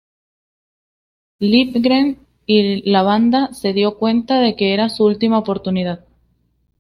/ˈdjo/